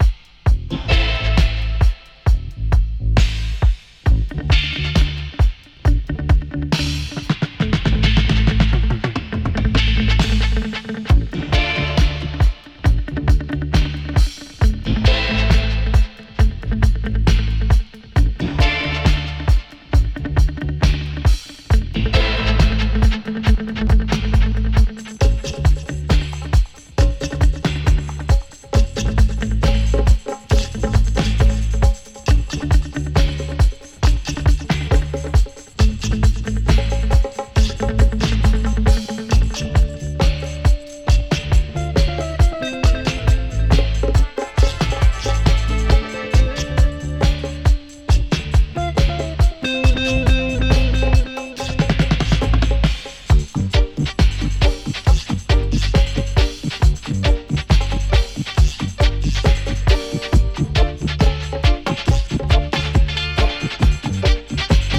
Reggae
Roots Reggae